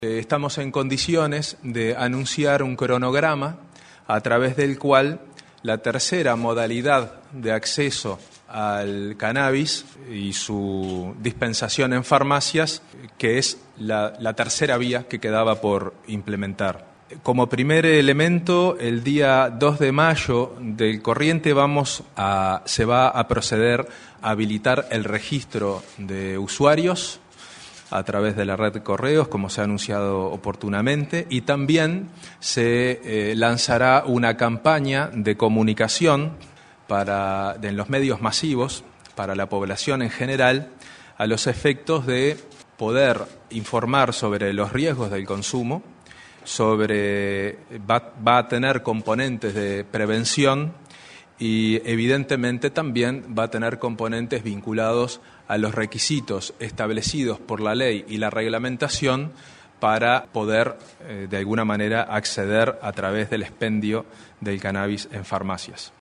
Los datos los dio en conferencia de prensa el presidente de la Junta y prosecretario de Presidencia, Juan Andrés Roballo: